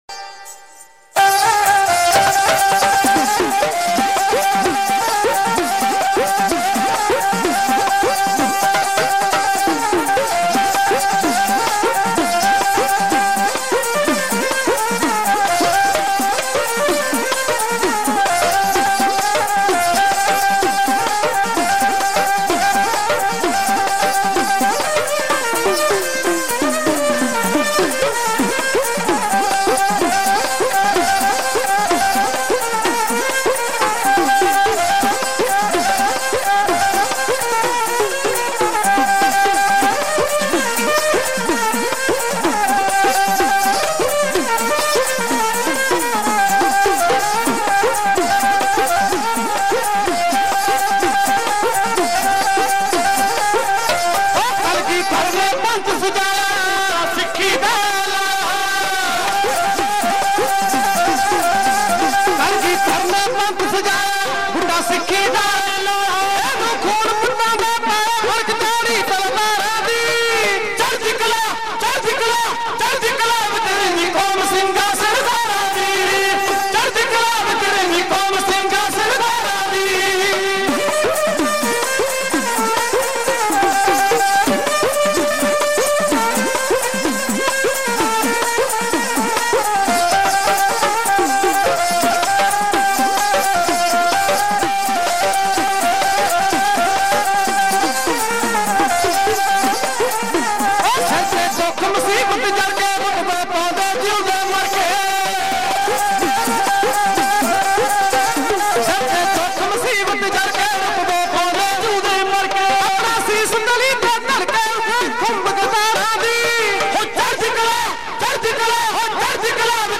Genre: Dhadi Vaara Album Info